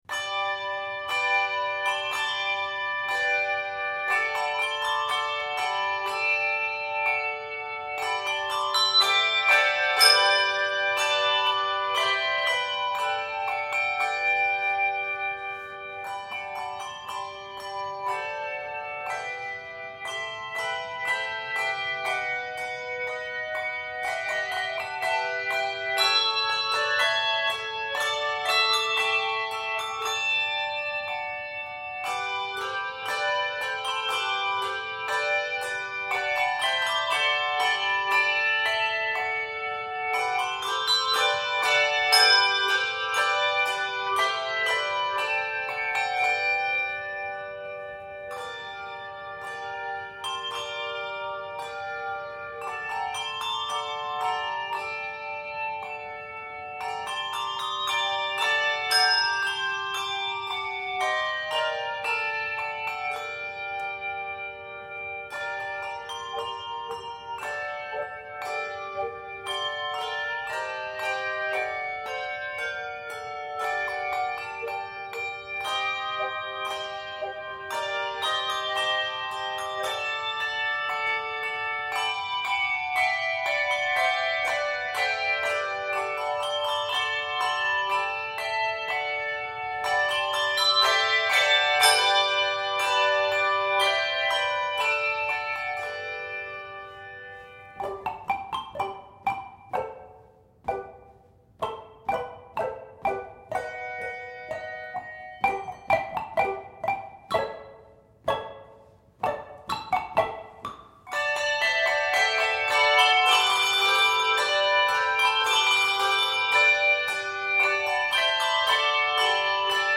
alive with joy
designed specifically for the two octave choir
it is scored in G Major and g minor.